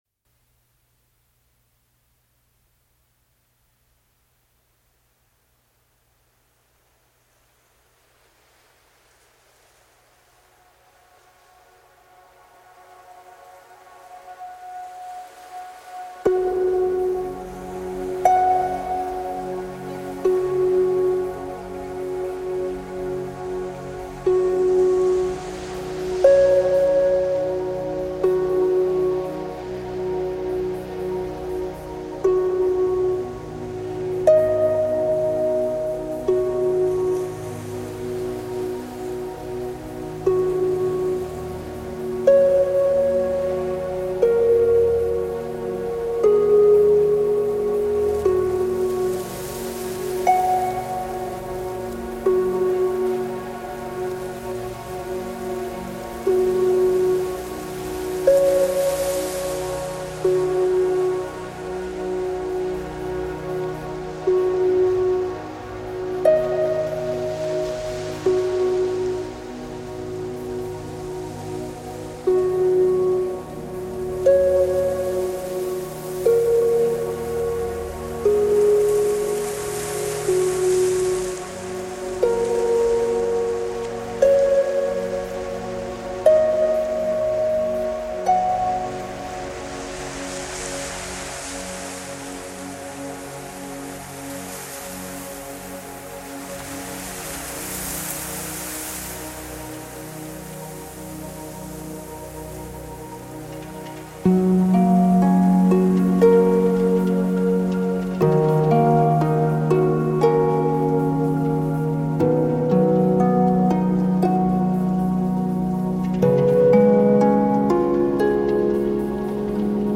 Trata-se de ouvir a sessão como música ambiente.